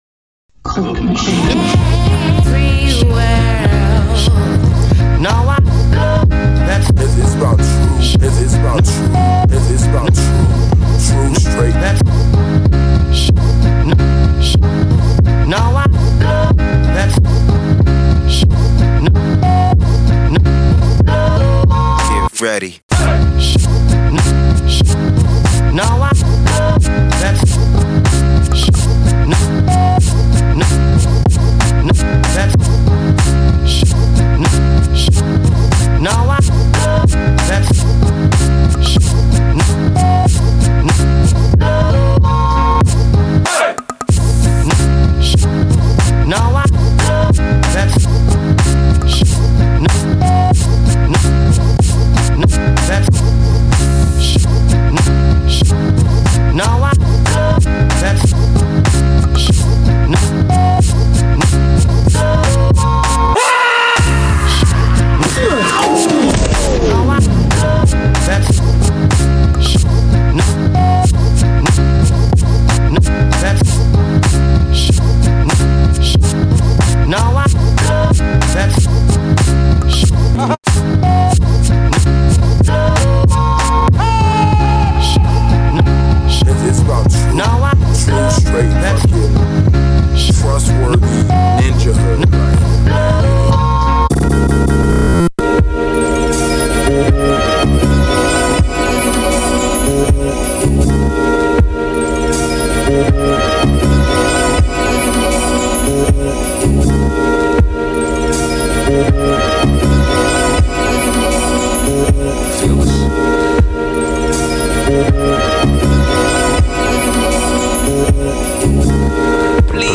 really cozy mid-tempo electronic and R&B